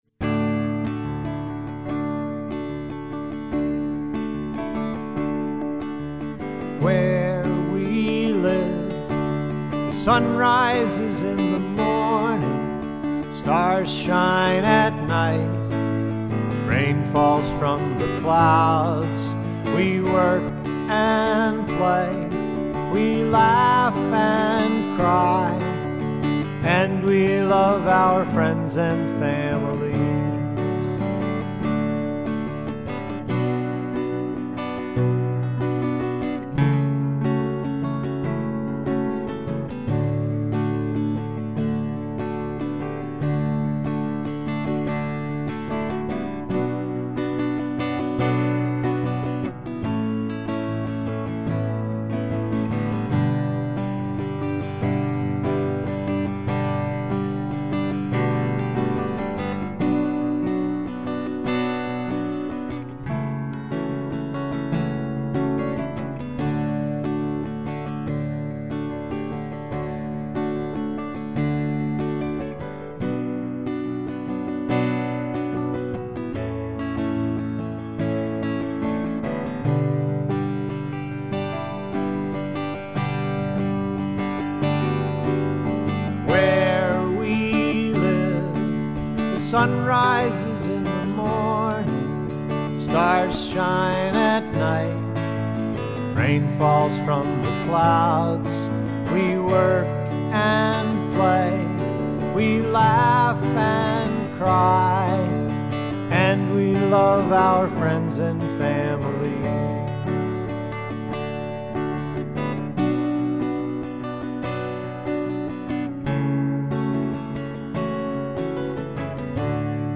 Chorus 0:00 to 0:31
Instrumental 0:33 to 1:25